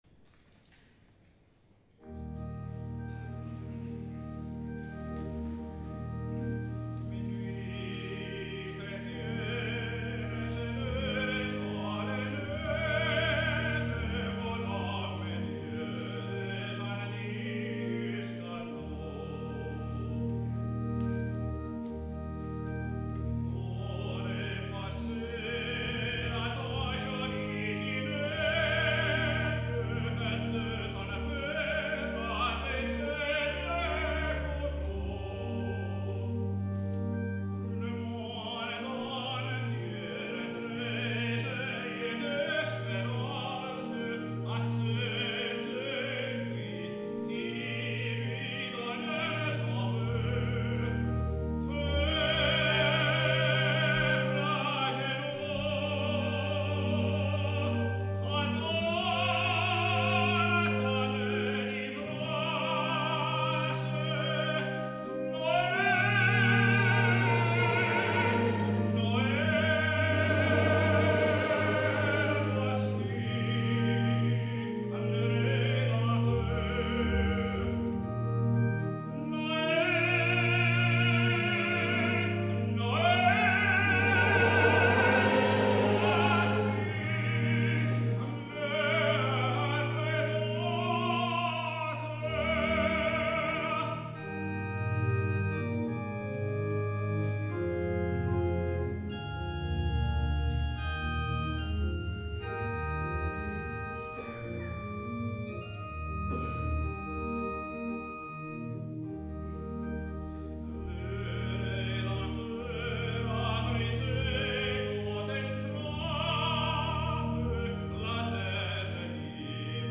S. Gaudenzio church choir Gambolo' (PV) Italy
Pieve di Sant'Eusebio
CONCERTO DI NATALE